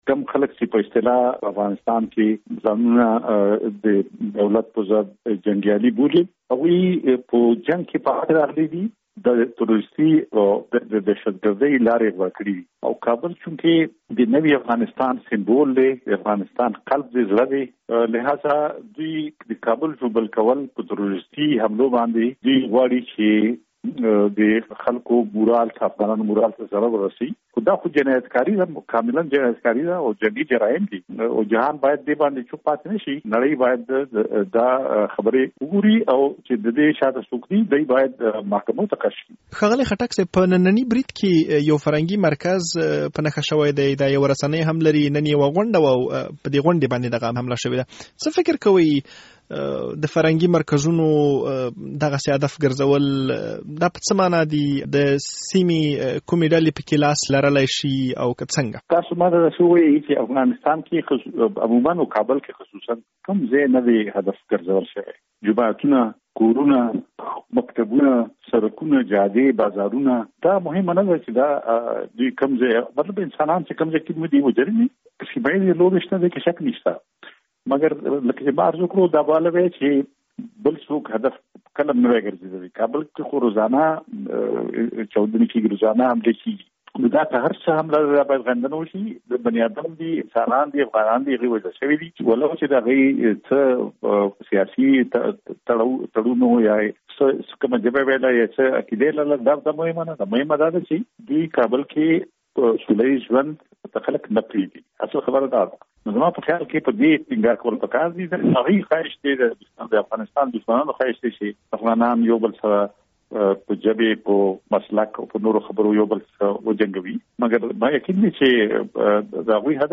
له ښاغلي افراسیاب خټک سره مرکه.